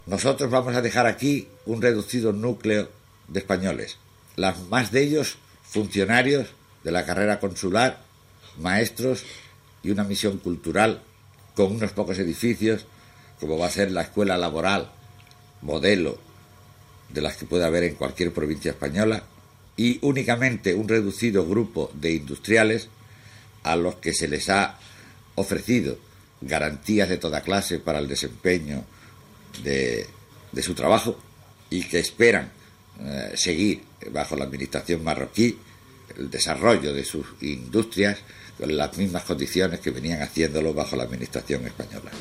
Informació de l'últim acte espanyol a la colònia de l'Ifni (Marroc) hores abans que aquesta passés a estar sota sobirania marroquina, Declaració del governador José Miguel Vega Rodríguez
Informatiu